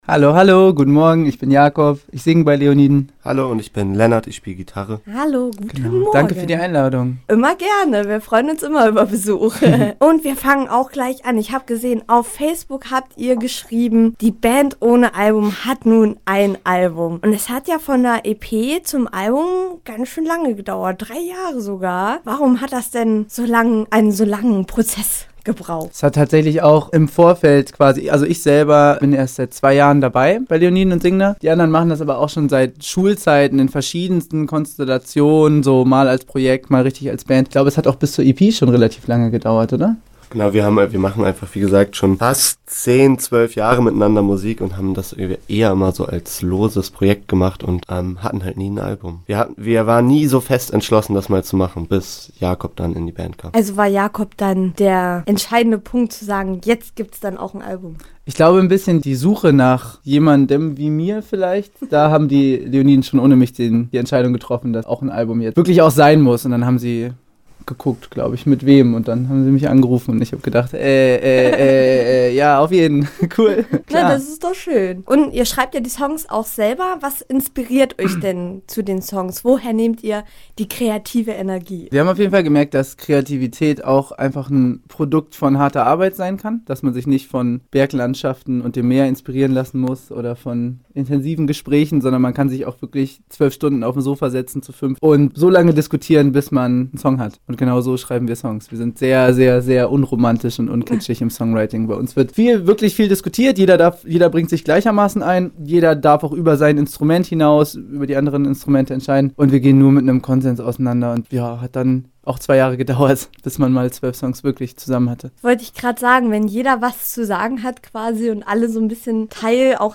Im Interview mit Leoniden - Campusradio Jena
Um euch aber nicht nur einen Einblick in das gleichnamige Album zu geben, sondern euch auch die Kieler Jungs vorzustellen, haben wir sie für euch ins Studio eingeladen.